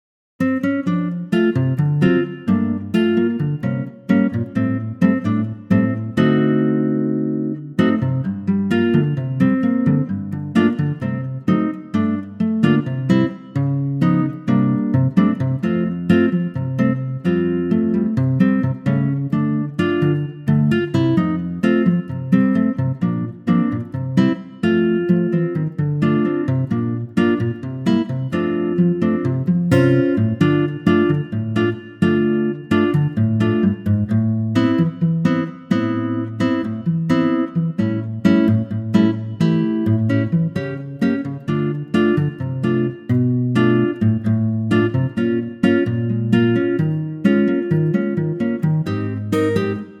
key - F - vocal range - C to A